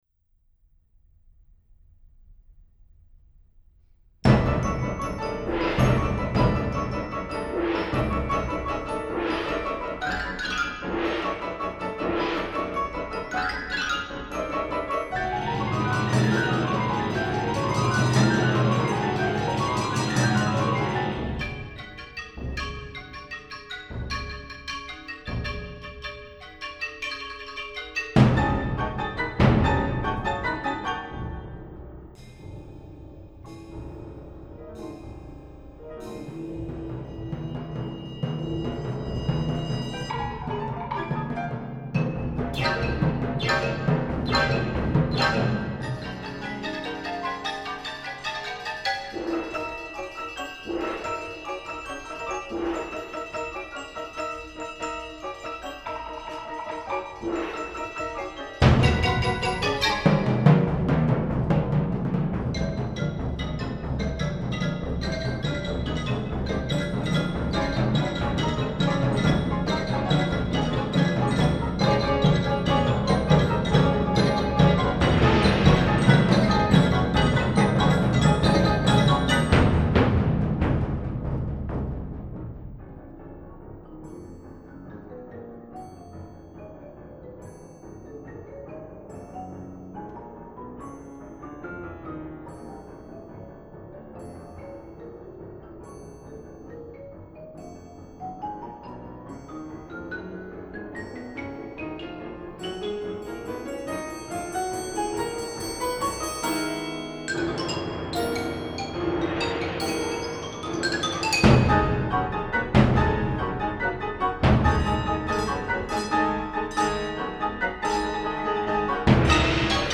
Machinemuziek
Composities die zich onderscheidden door hun experimentele vorm, de mechanische klank en een bijna geweldadige dynamiek.
Acht piano's, een pianola, vier xylofoons, twee elektrische bellen, twee vliegtuigpropellers, een tamtam (op een trommel gelijkend slaginstrument), vier grote trommen en een elektrische sirene George Antheil, die als jongeman naar de wereldstad Parijs was gekomen, was een groot bewonderaar van Stravinsky.